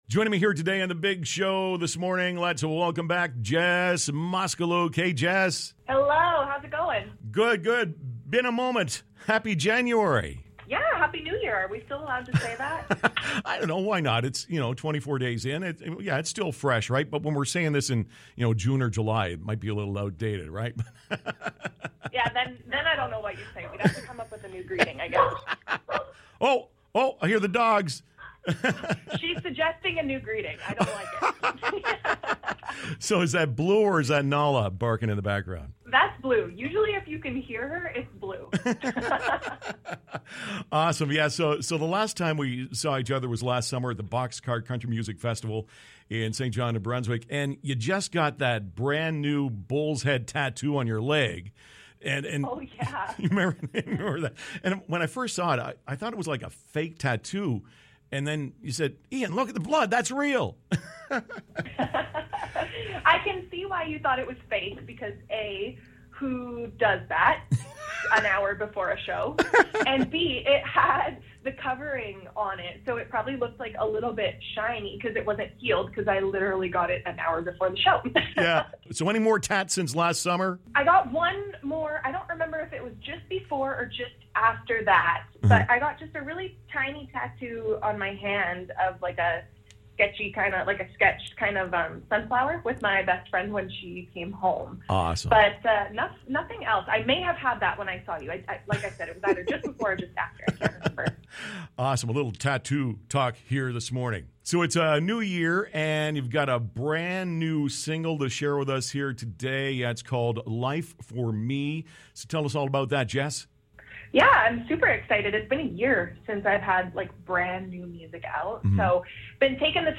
Have a listen to our chat below, we talk about the new song, plus, some tattoo talk, embarrassing moments on stage and some Hot Fire questions: